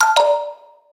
notification-sound.mp3